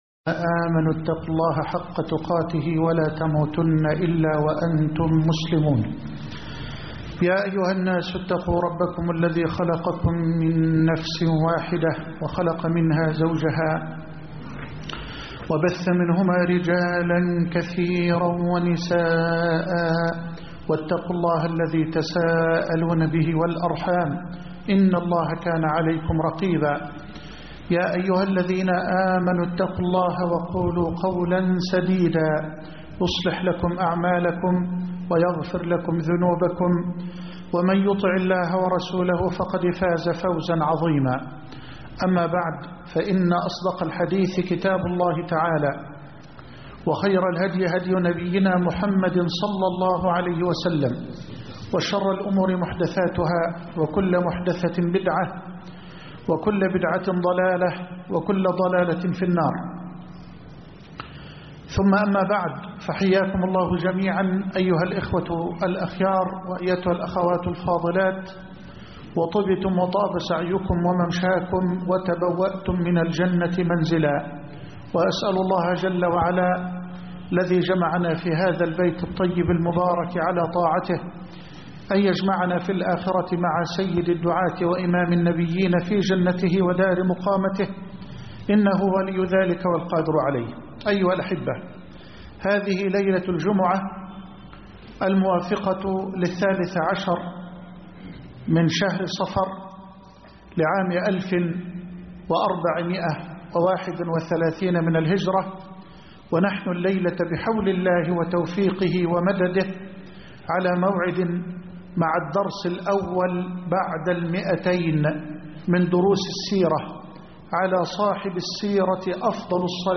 الدرس (201) لا تيأسوا من روح الله (1/2/2010) السيرة النبوية - فضيلة الشيخ محمد حسان